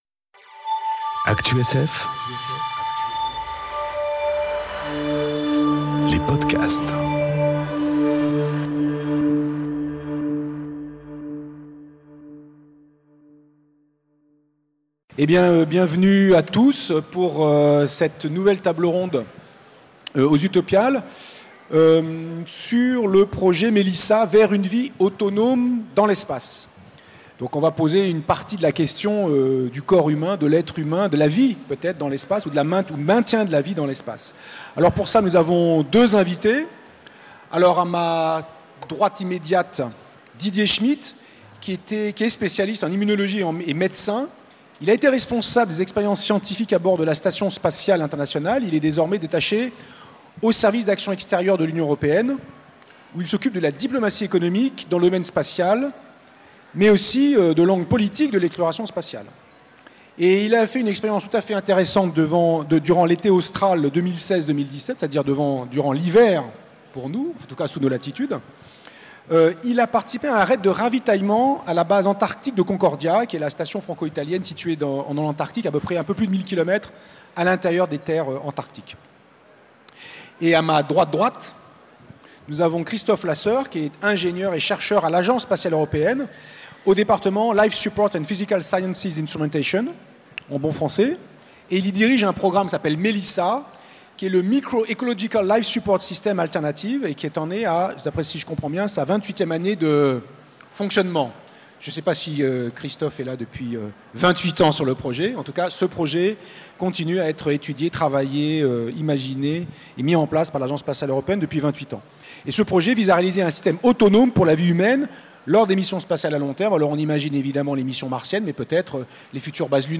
Conférence Projet MELiSSA : vers une vie autonome dans l’espace enregistrée aux Utopiales 2018